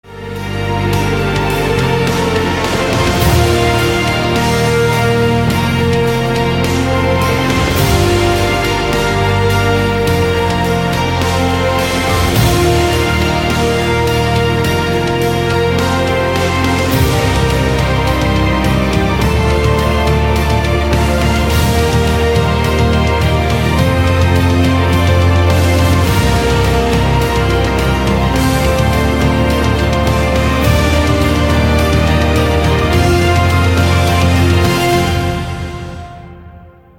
громкие
красивые
без слов
инструментальные
эпичные
Эпическая и оркестровая музыка